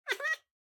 Minecraft Version Minecraft Version latest Latest Release | Latest Snapshot latest / assets / minecraft / sounds / mob / cat / ocelot / idle3.ogg Compare With Compare With Latest Release | Latest Snapshot